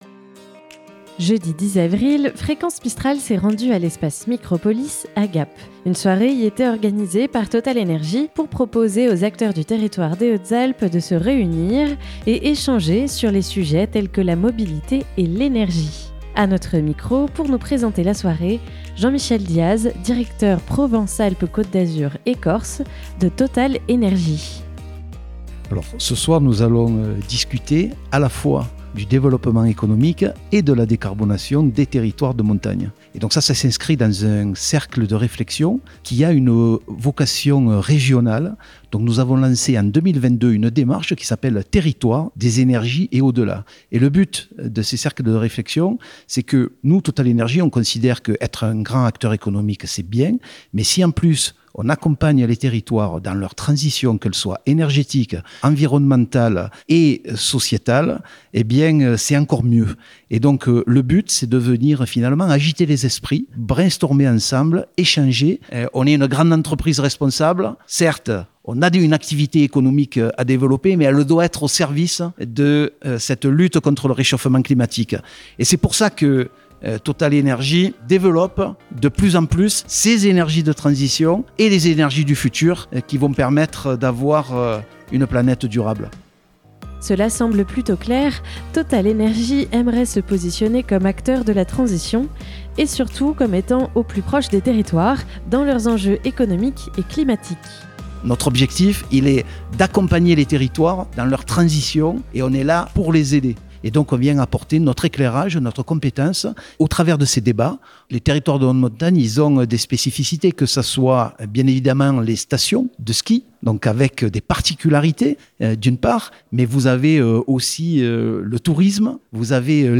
Introduction à la 1e table ronde.